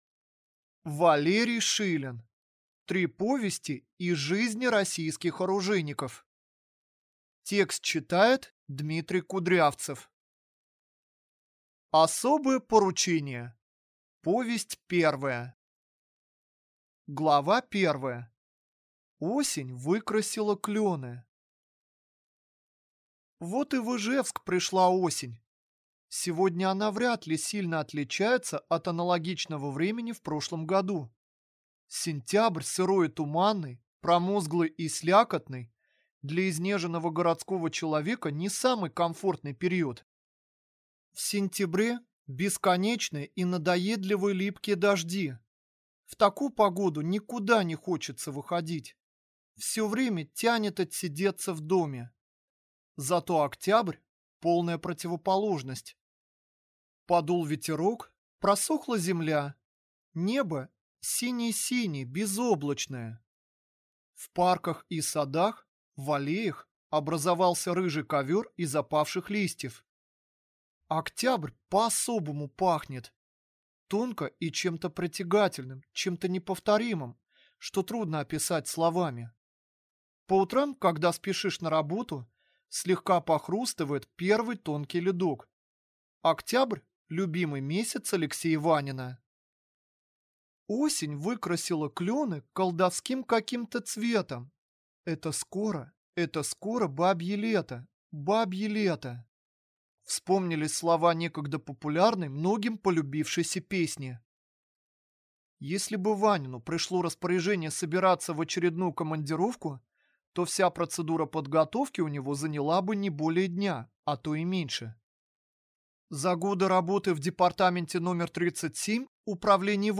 Аудиокнига Три повести из жизни российских оружейников | Библиотека аудиокниг